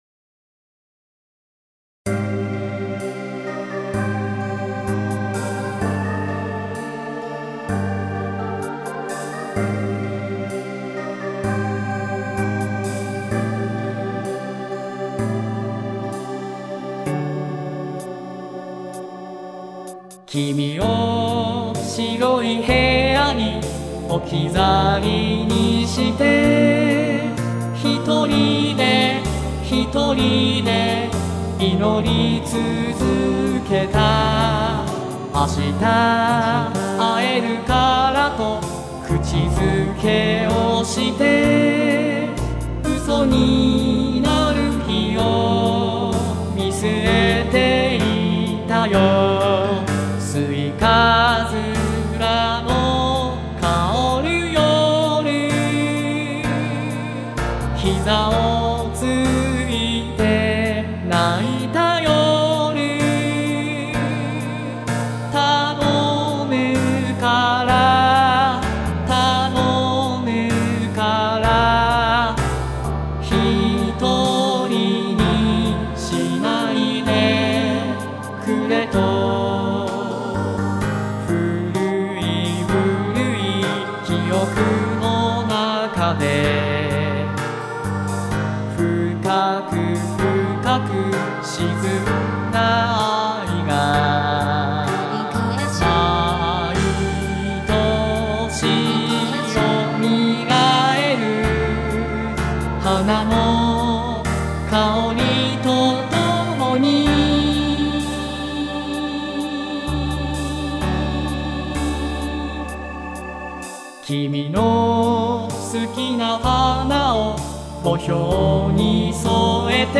* [　　] 内はコーラス
今回ラストの「待つよ」の部分をがくぽウィスパーに歌わせてみて合成したのだが，なぜか「待つわ」に聞こえる。
単独で出力した時点では「待つよ」なのだが，伴奏その他にあわせると「待つわ」にしか聞こえなくなる。